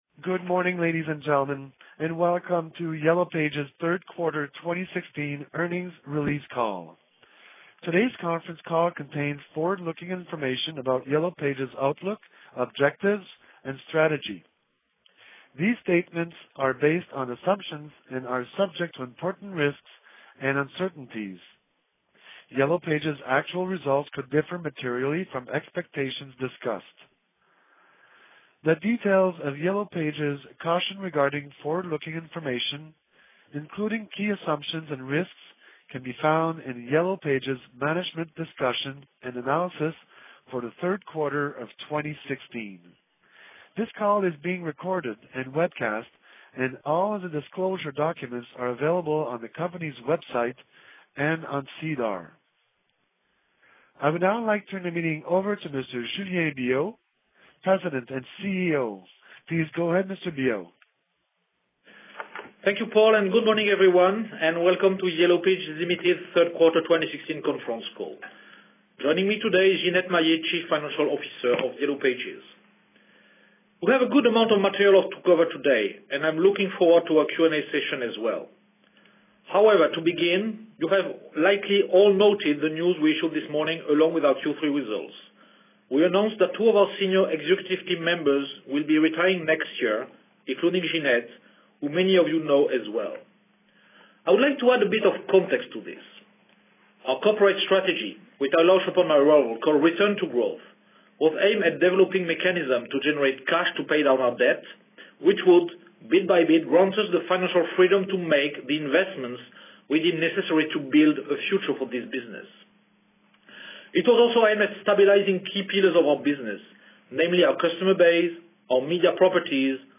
Webdiffusion de la conférence téléphonique (en anglais)